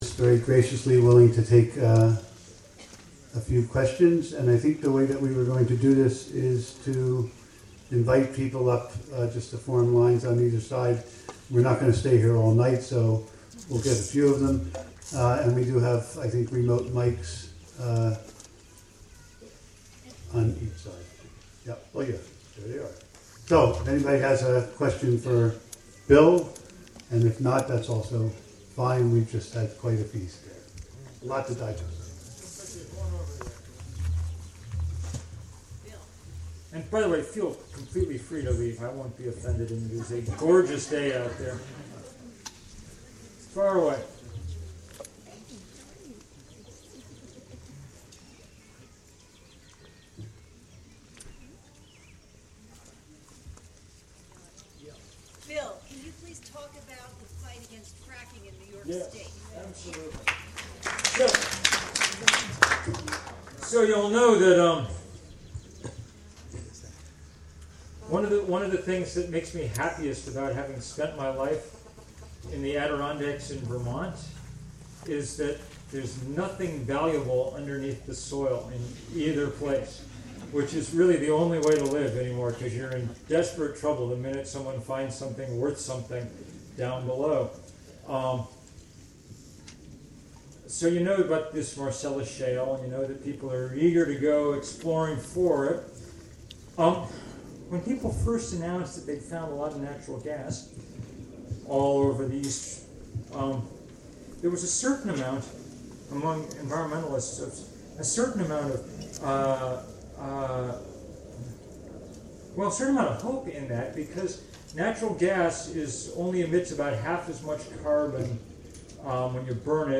Bill McKibben question and answer session at Hawthorne Valley.
Hawthorne Valley Farm